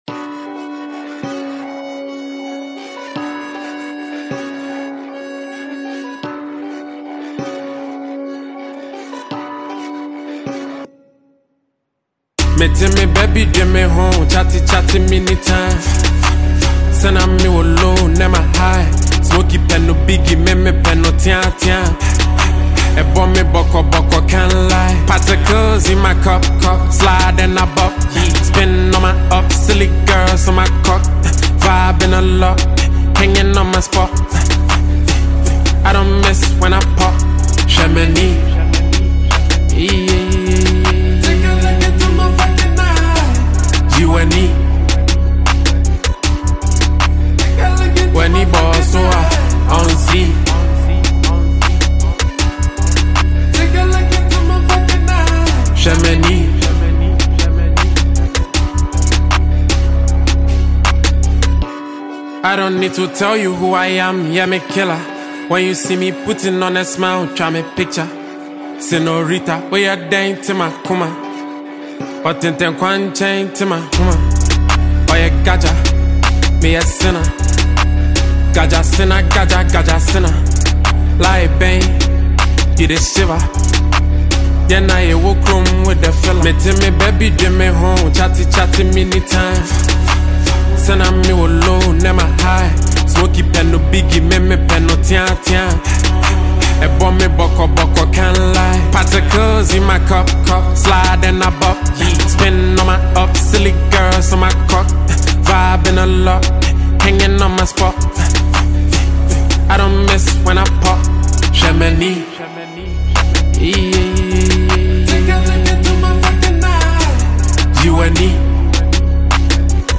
Ghanaian Hiphop singer
a captivating hiphop-hiplife free mp3 song for download.